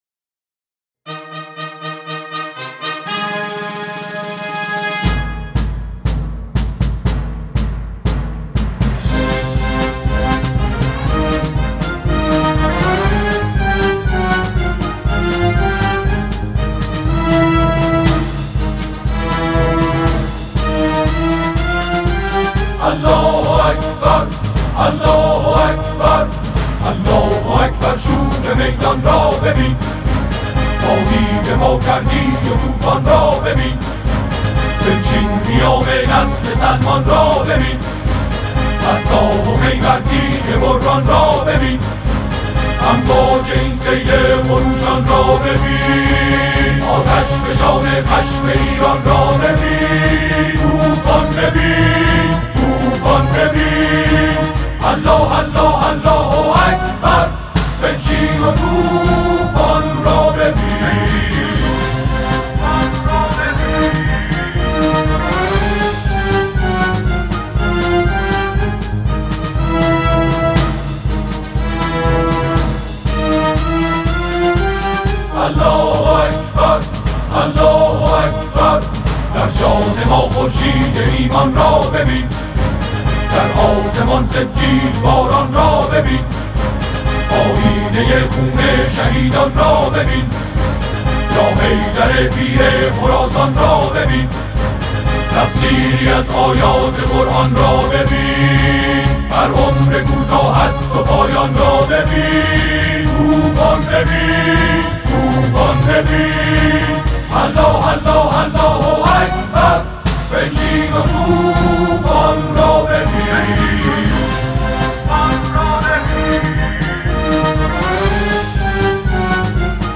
اثر حماسی